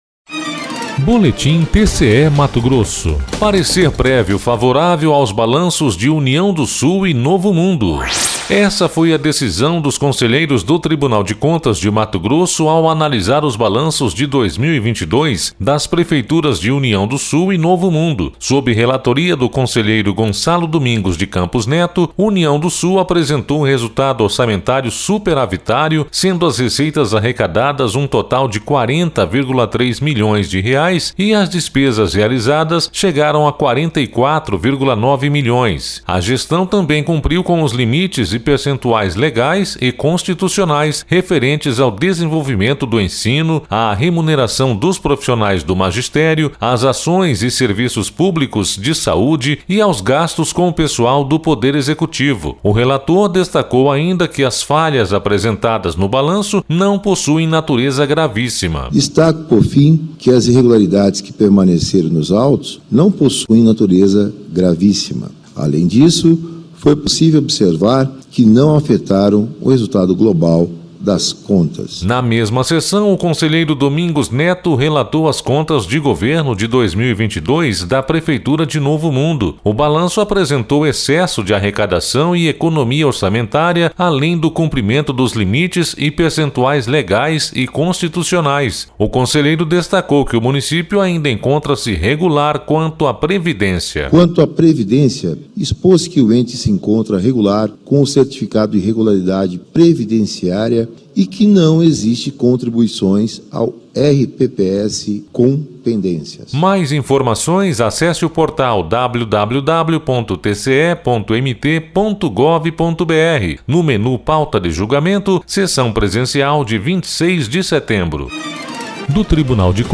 Sonora: Gonçalo Domingos de Campos Neto – conselheiro do TCE-MT